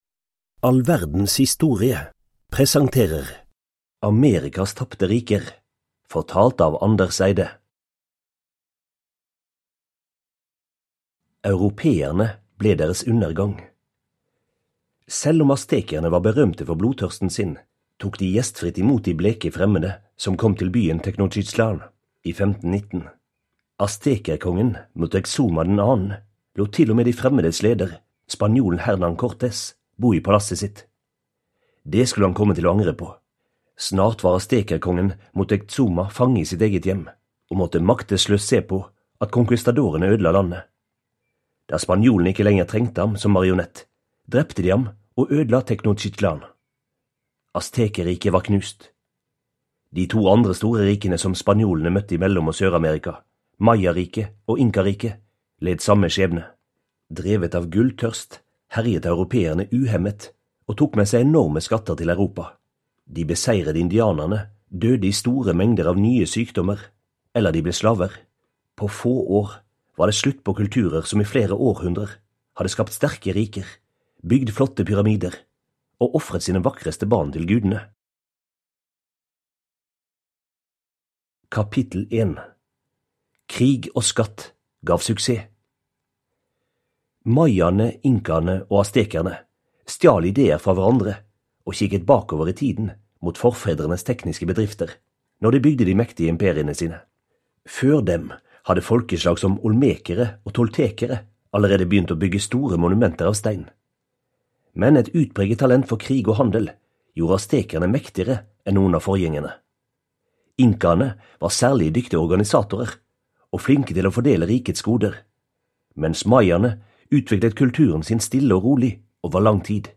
Amerikas tapte riker (ljudbok) av All verdens historie